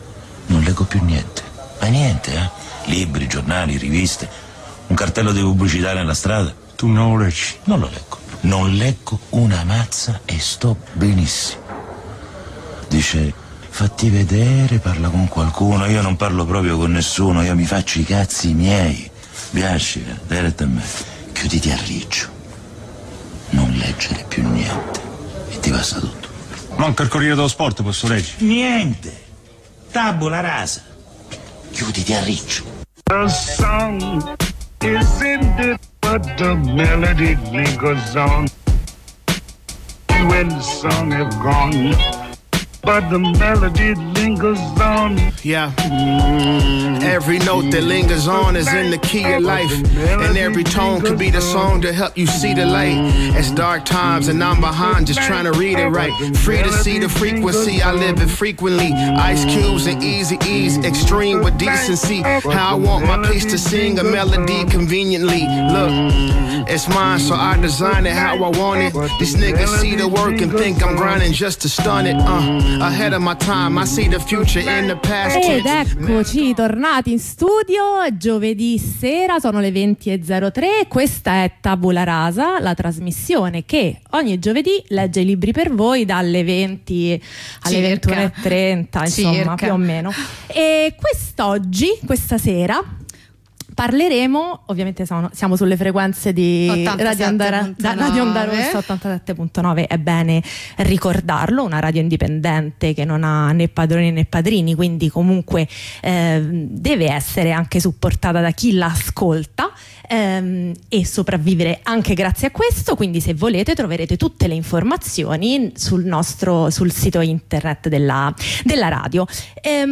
La trasmissione che legge i libri per voi.